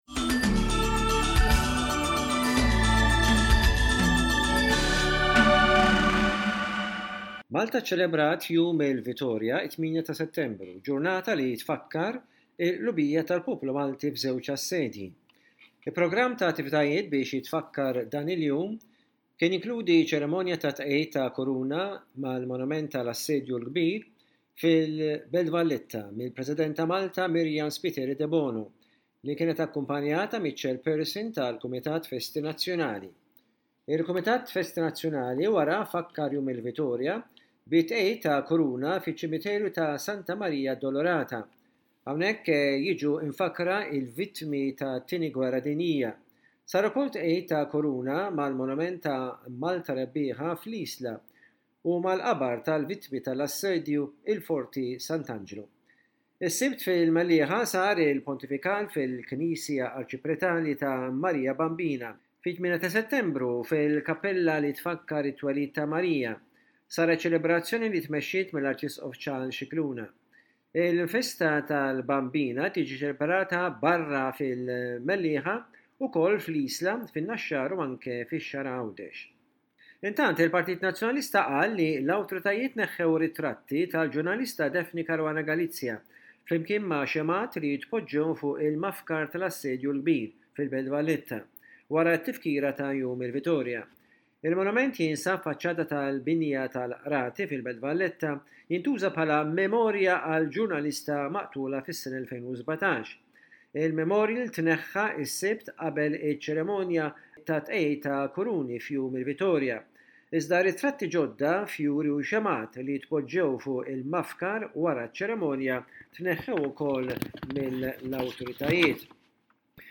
Minbarra l-aspett reliġjuż, il-festa tat-twelid tal-Madonna, dan il-jum ifakkar ukoll it-tmiem ta’ żewġ assedji fuq Malta: l-Assedju l-Kbir tal-1565, u dak fl-1943, it-tmiem tat-Tieni Gwerra Dinjija. Rapport